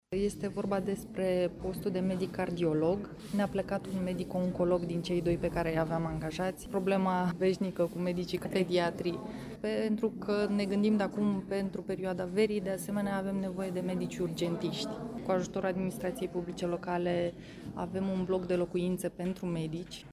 Ca să ajute unitățile sanitare să-și acopere deficitul de doctori, Direcția de Sănătate Publică din Constanța a organizat, ieri, la Facultatea de Medicină a Universității Ovidius o întâlnire între medicii aflați în căutarea unui loc de muncă, reprezentanții spitalelor și cei ai autorităților locale și județene din Dobrogea.